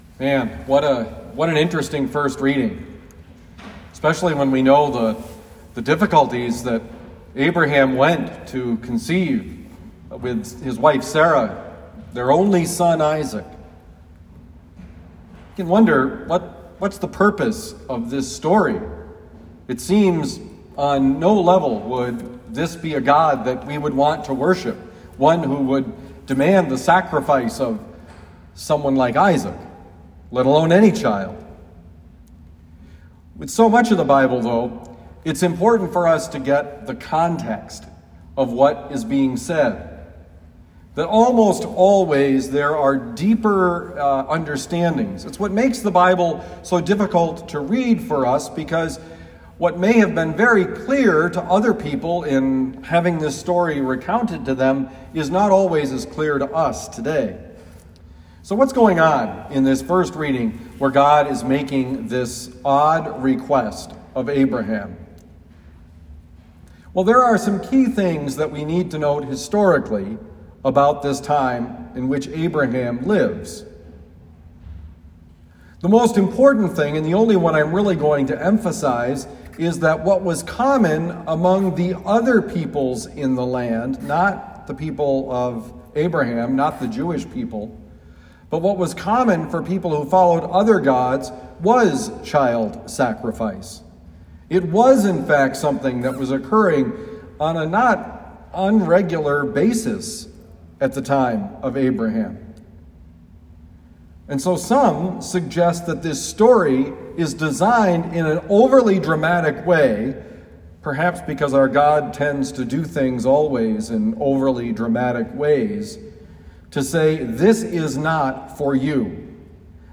Homily for Sunday, February 28, 2021
Given at Our Lady of Lourdes Parish, University City, Missouri.